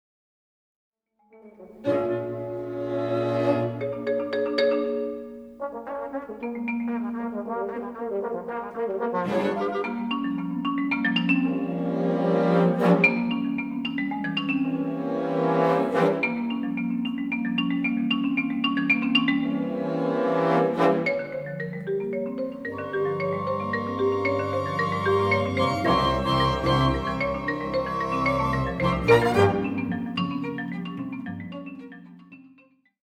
solo marimba & chamber orchestra
(fl, ob, cl, bn, hn, tp, tb, strings)